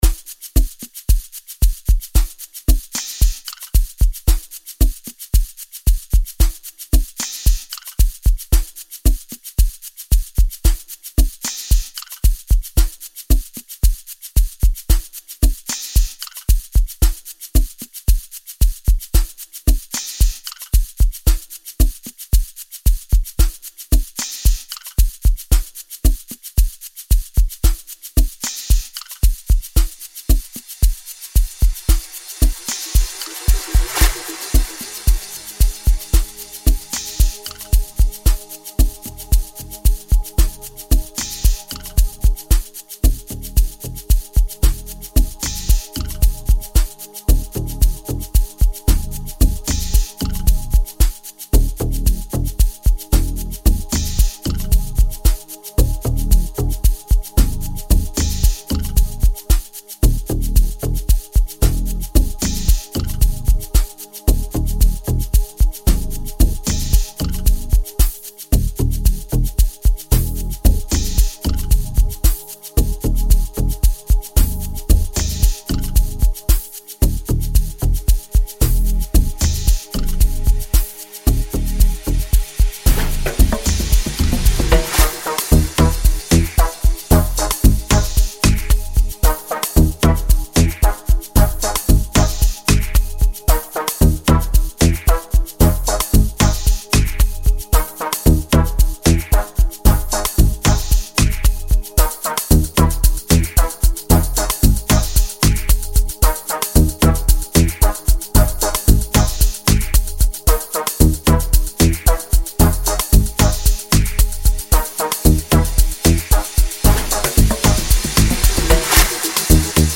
Soulful piano, Sgija, and Kwaito elements